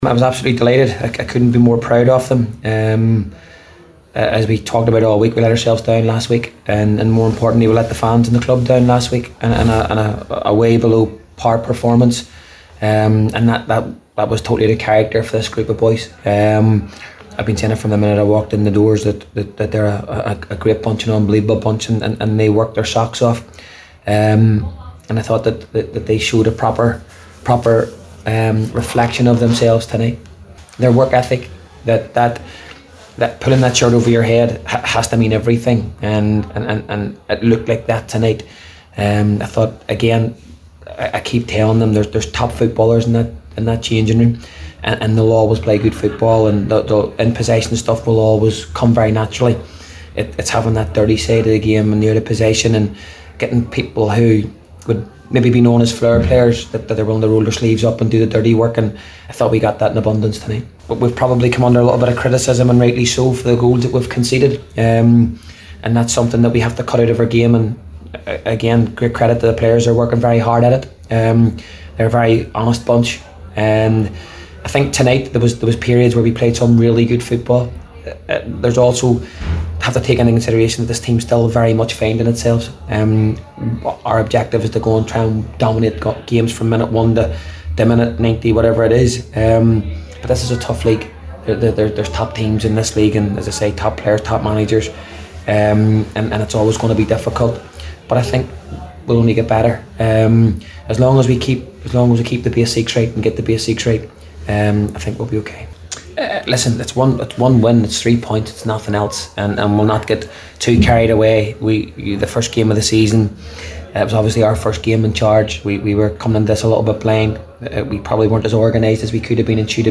spoke to the assembled media and said he was proud of the players for bouncing back after a disappointing defeat to Waterford last week…